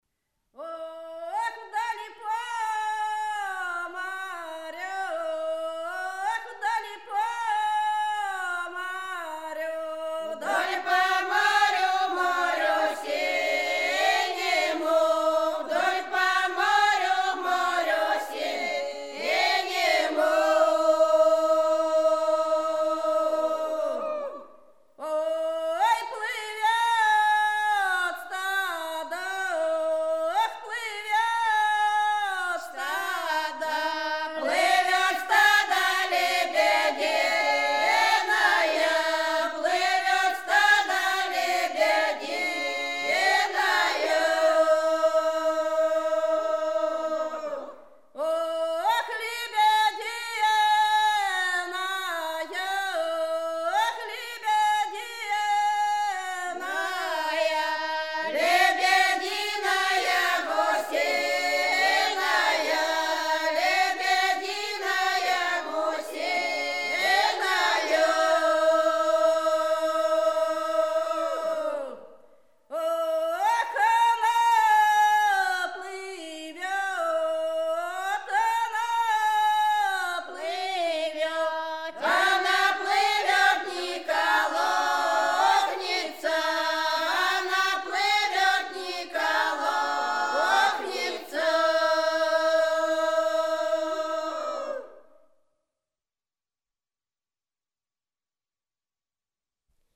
Рязань Секирино «Ох, вдоль по марю», круговая.